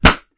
气球 " 随机气球声音
描述：一些未使用的气球响起